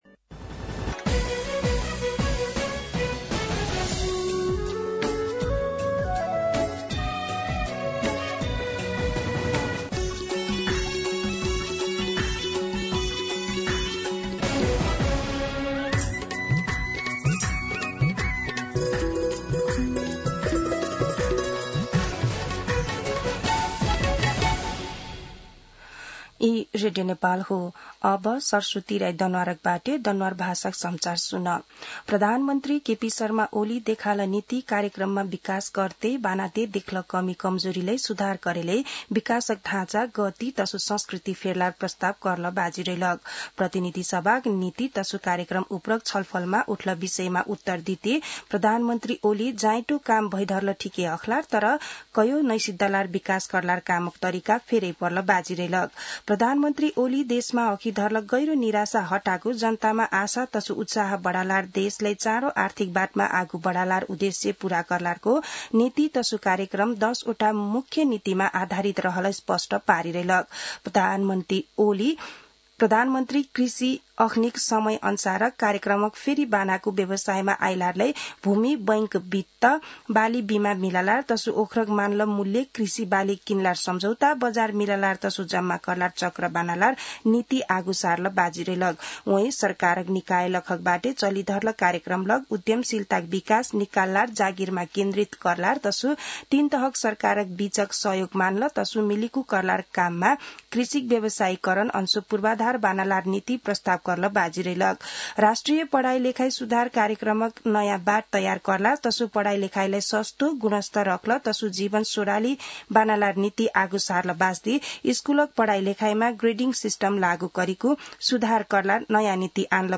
दनुवार भाषामा समाचार : २८ वैशाख , २०८२
Danuwar-News-2.mp3